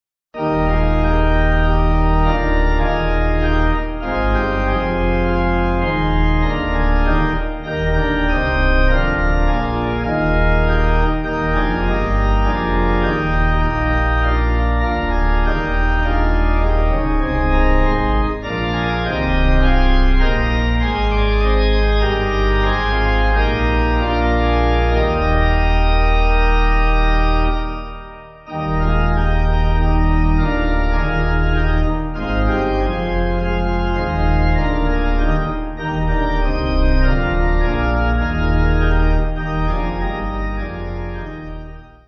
(CM)   6/Eb